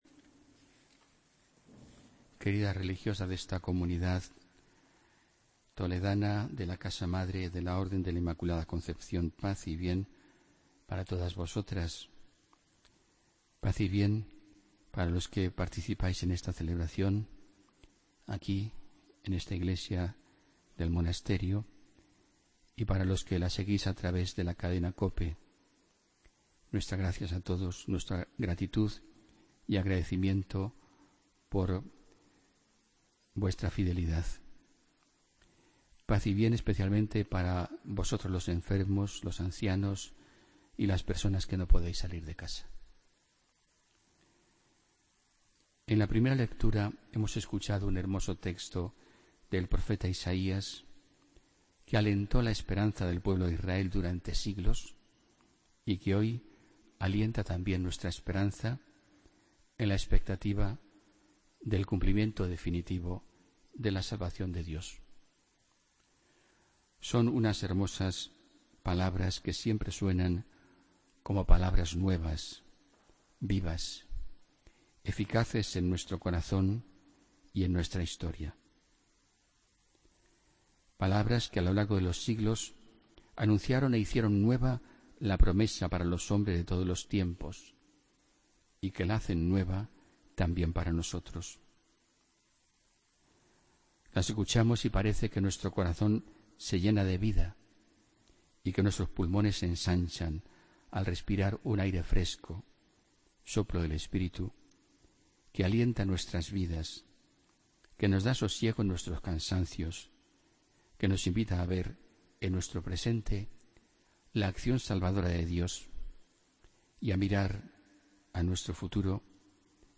Homilía del domingo 04 de diciembre de 2016